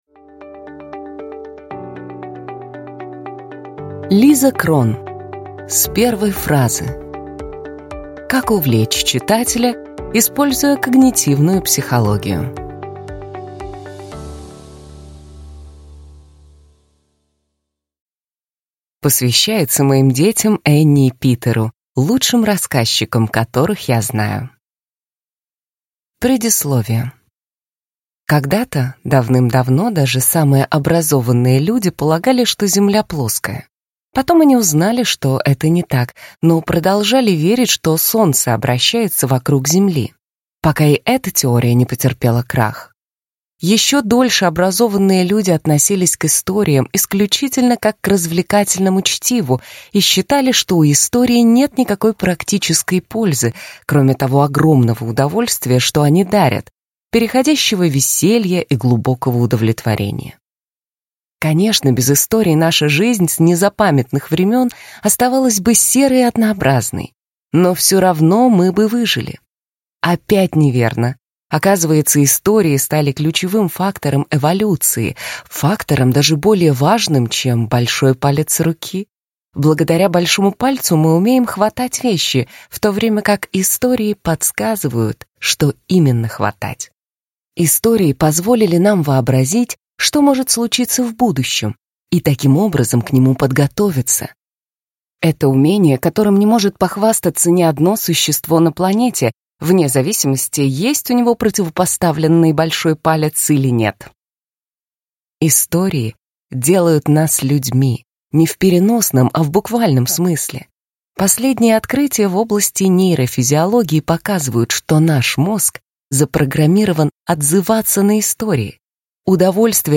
Аудиокнига С первой фразы: Как увлечь читателя, используя когнитивную психологию | Библиотека аудиокниг